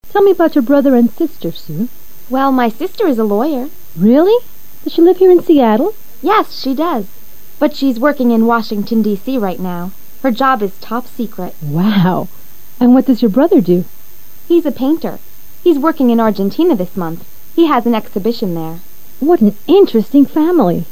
Listening Test 6/16
LISTENING TEST 6/16: Ahora, basándote en los comentarios del diálogo anterior, selecciona la opción más adecuada para cada pregunta.